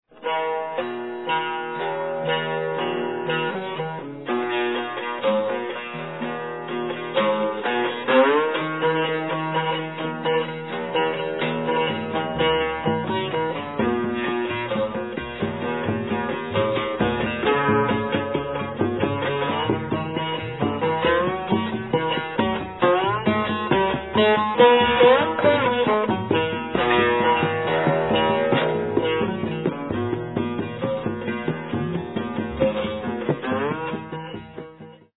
Sarod of India